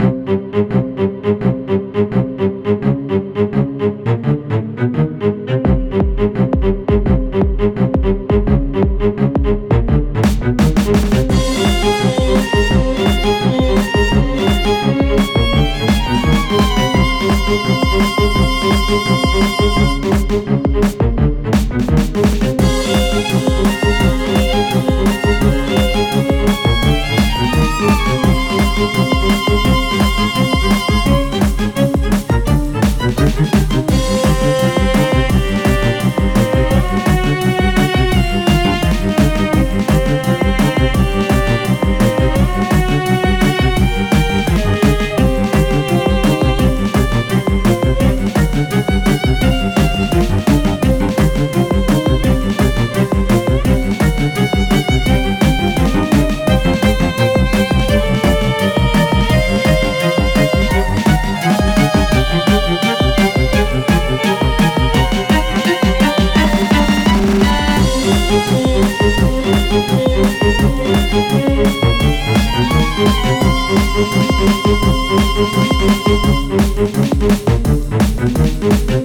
💬過去に深い悲しみを背負ったキャラを表現した弦楽四重奏風の戦闘BGMです。